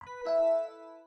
Звук завершения сеанса в Windows 8 (Windows Logoff)